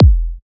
edm-kick-24.wav